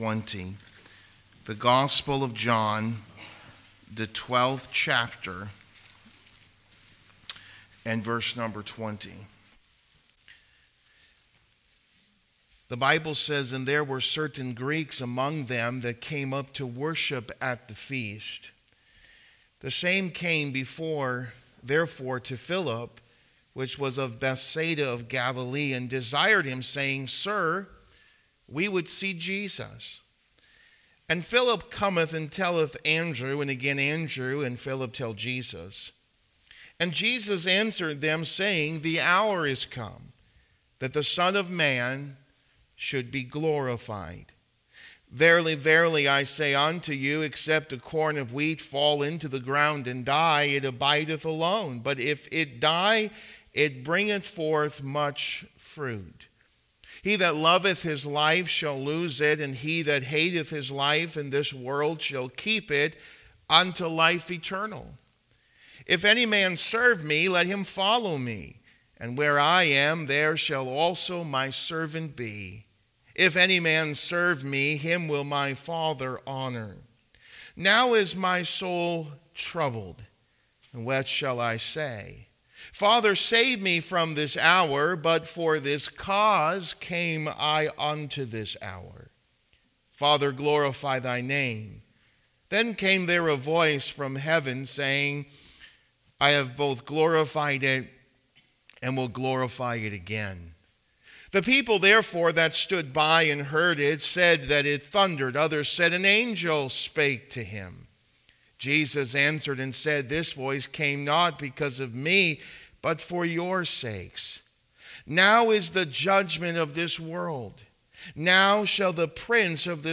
What Death He Should Die | Sermons